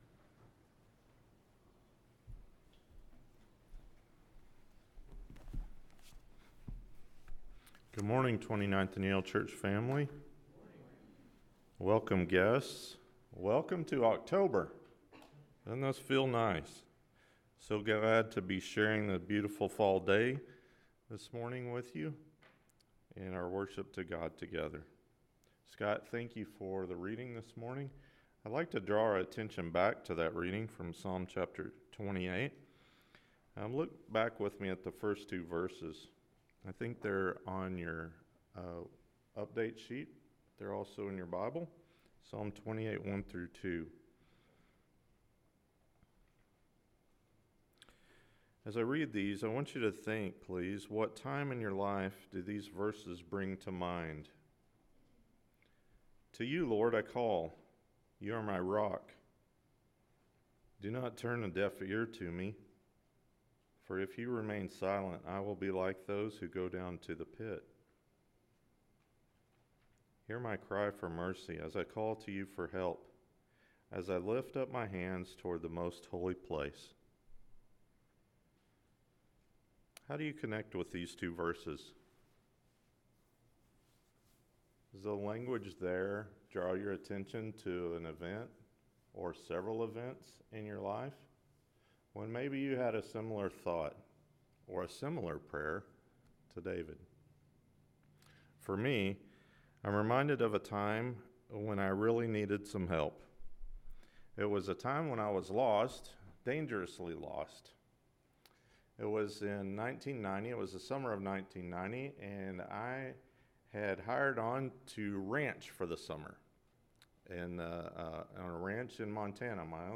Kingdom Stories: The Lost Sheep & The Lost Coin – Luke 15:1-10 – Sermon — Midtown Church of Christ